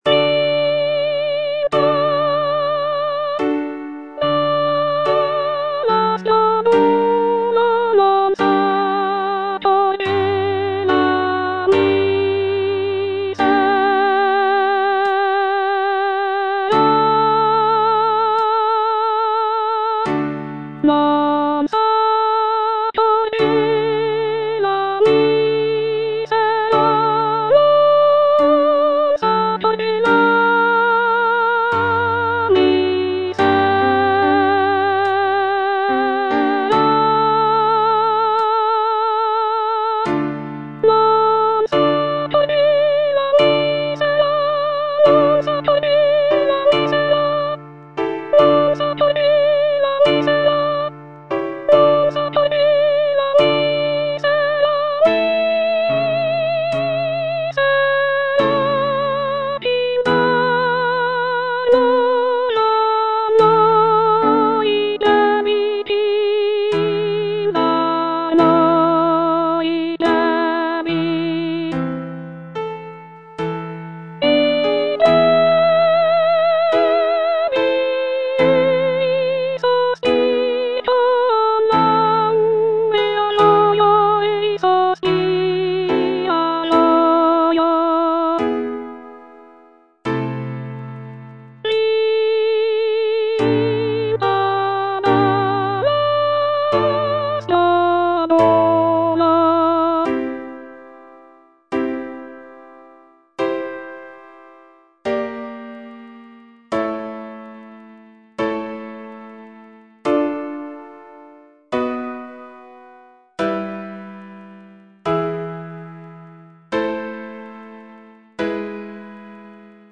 C. MONTEVERDI - LAMENTO D'ARIANNA (VERSION 2) Coro III: Vinta da l'aspro duolo (soprano I) (Voice with metronome) Ads stop: auto-stop
It is a deeply emotional lament aria that showcases the singer's ability to convey intense feelings of grief and despair.
The music is characterized by its expressive melodies and poignant harmonies, making it a powerful and moving example of early Baroque vocal music.